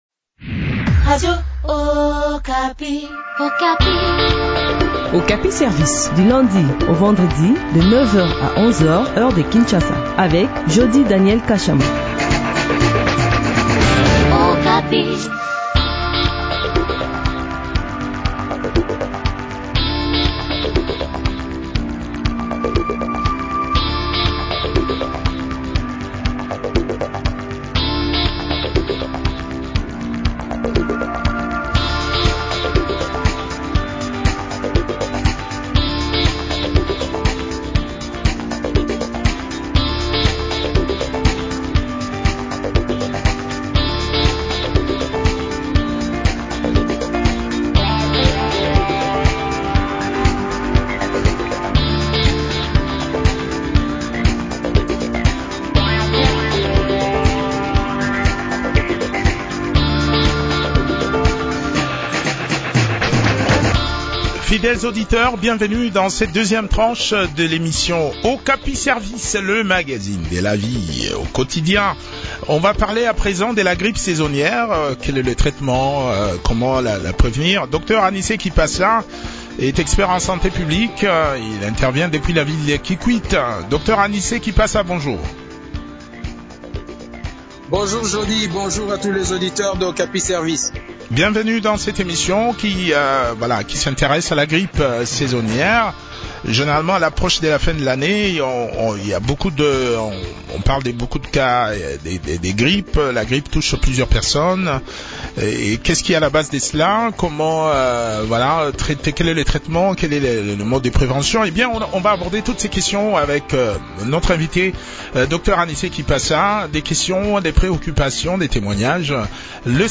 expert en santé publique.